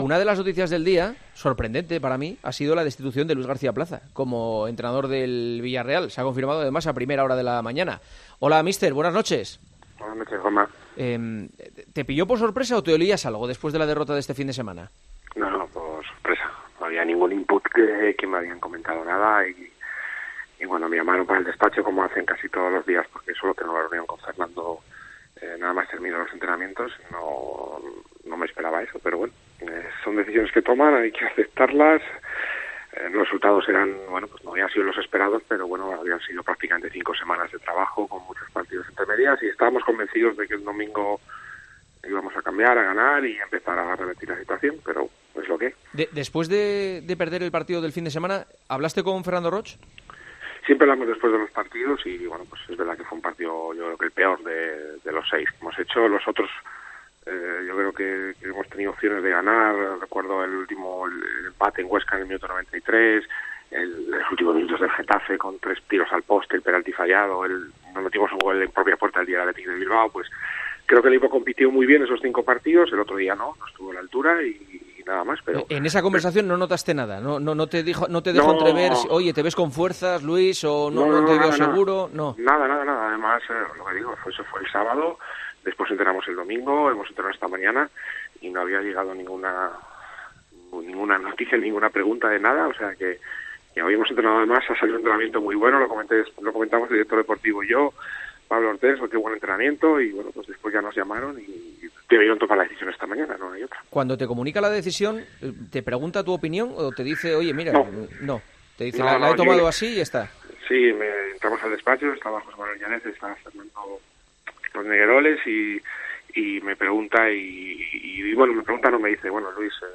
El perjudicado en esta ocasión es el ya ex entrenador Luis García Plaza, que este martes atendió la llamada de El Partidazo de COPE para contar sus sensaciones: "La llamada del Villarreal me pilló por sorpresa, son decisiones que se toman y hay que aceptarlas".